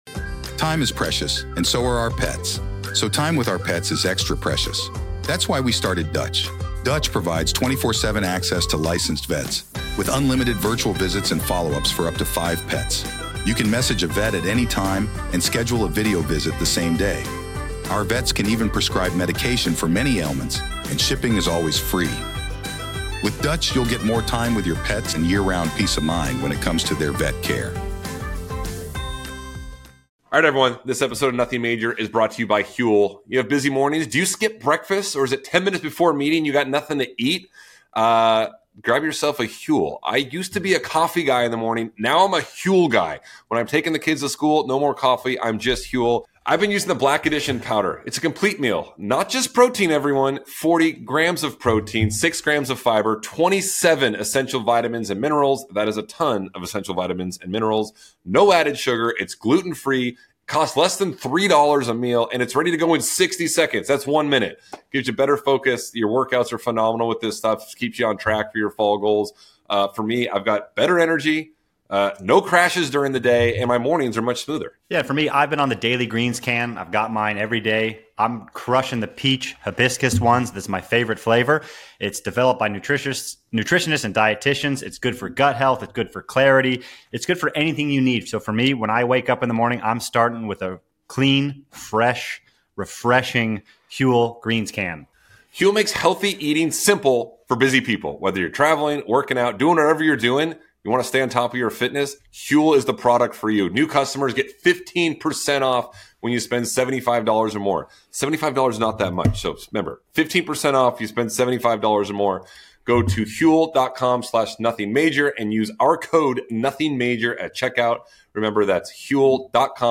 Interview with Alex Michelsen